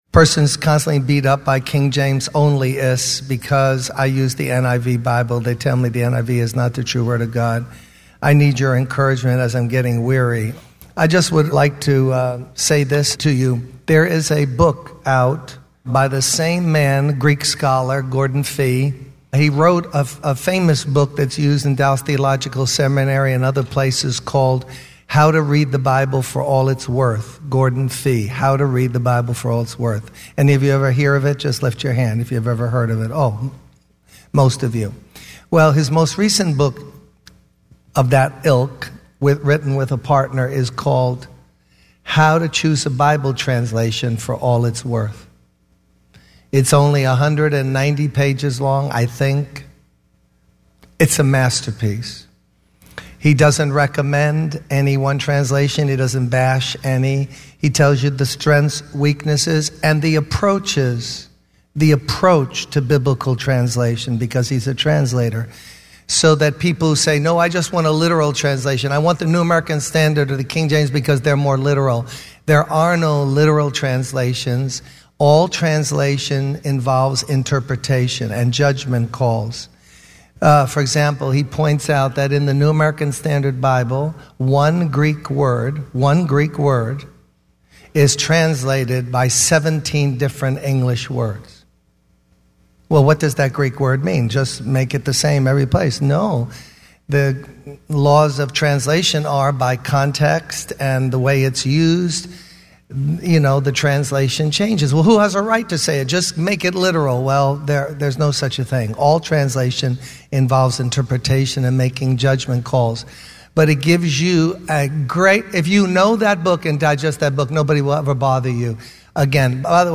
In this sermon, the speaker emphasizes the importance of studying and learning the word of God.